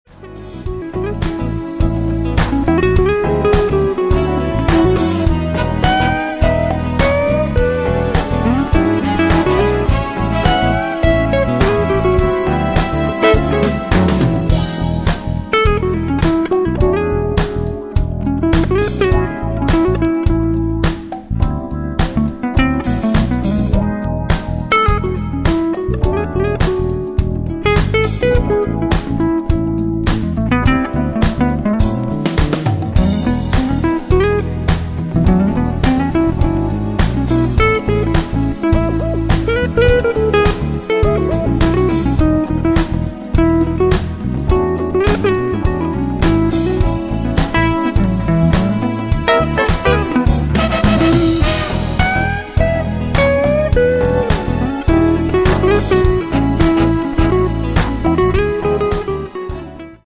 Smooth Jazz